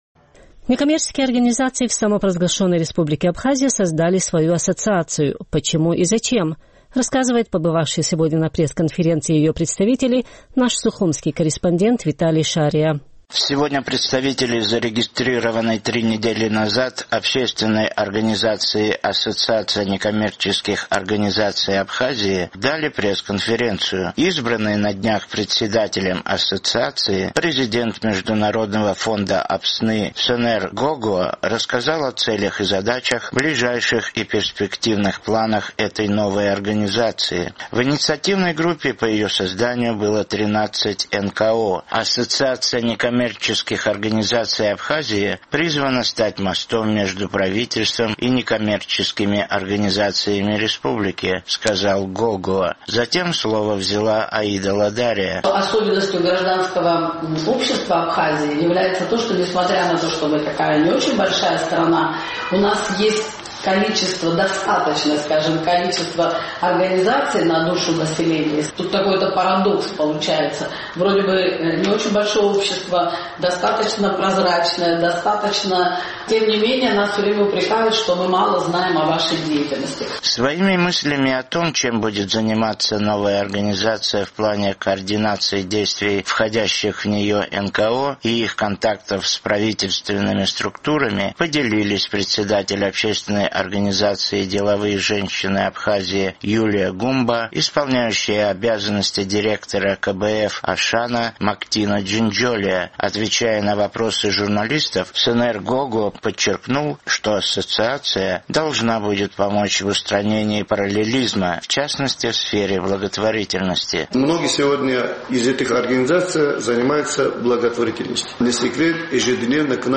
Сегодня представители зарегистрированной три недели назад общественной организации «Ассоциация некоммерческих организаций Абхазии» дали пресс-конференцию.